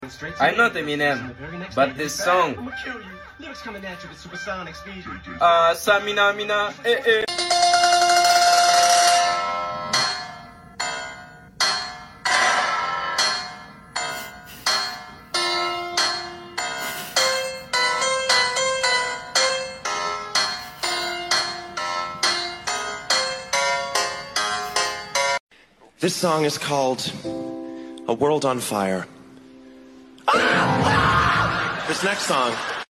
Ranking Top 5 Funniest unexpected sound effects free download